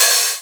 Open hat 4.wav